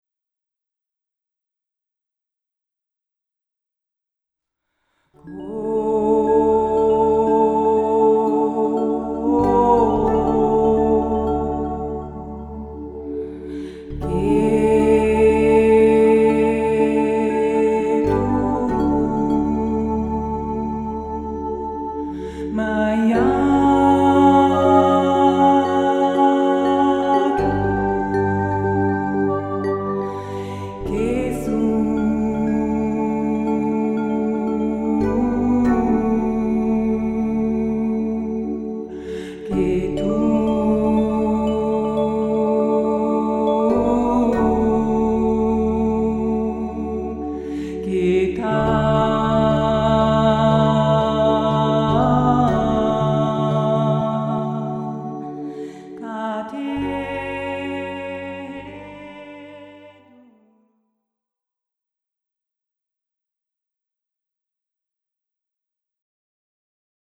Momentum-Aufnahmen